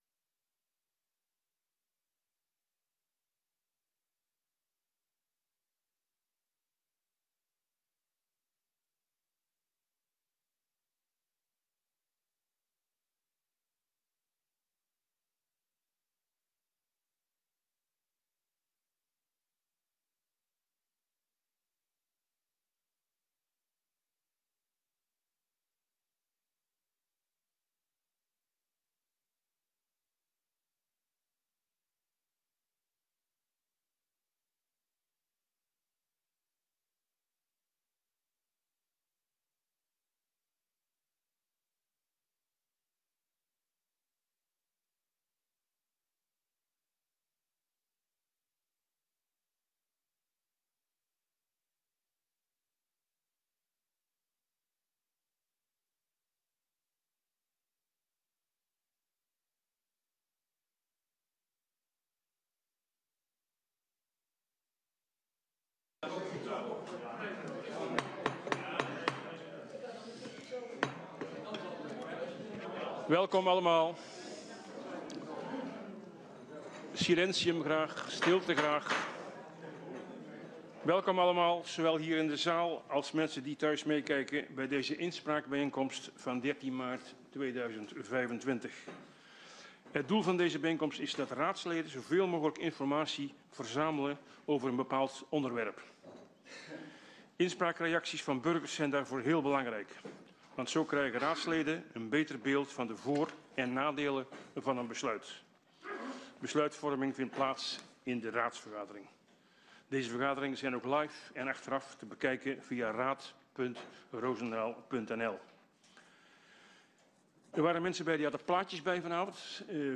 Inspraakbijeenkomst 13 maart 2025 19:00:00, Gemeente Roosendaal
Locatie: Raadzaal